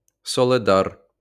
発音[ヘルプ/ファイル]）は、ウクライナ東部ドネツィク州オーブラスチ）のバフムート地区ウクライナ語版英語版である[2]。